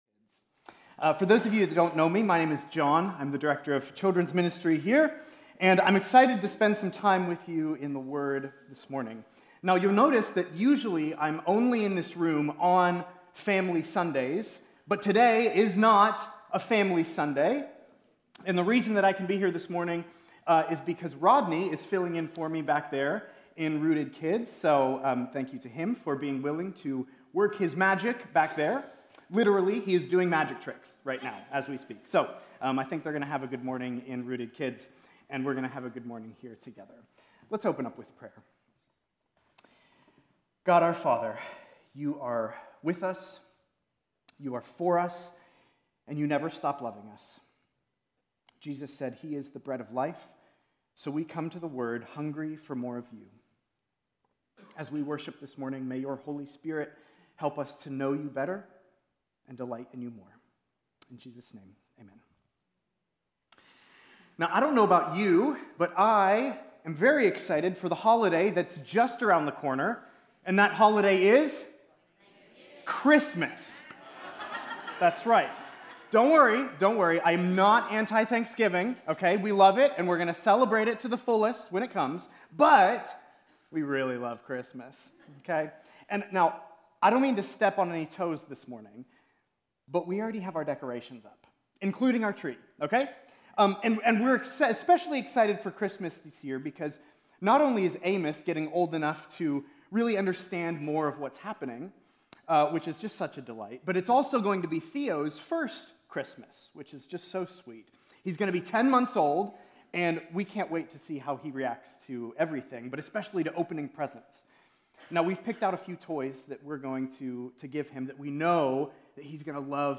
Sermons | Campbellsville Christian Church